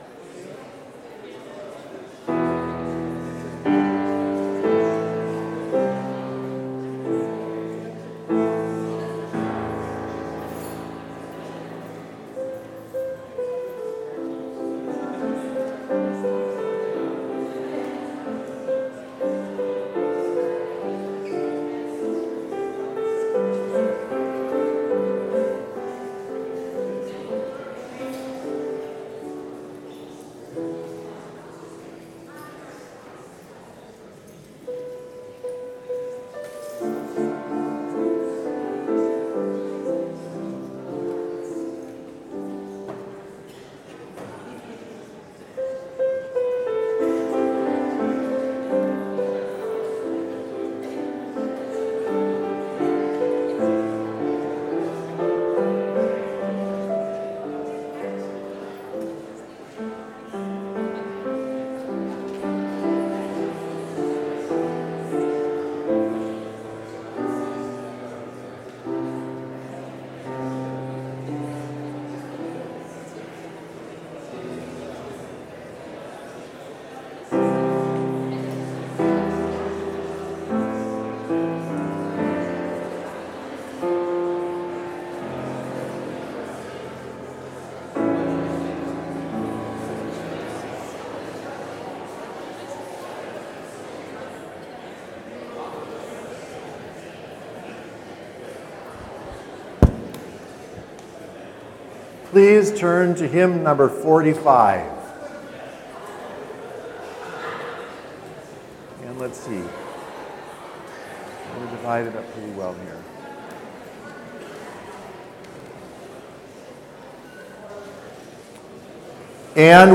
Complete service audio for Chapel - September 30, 2021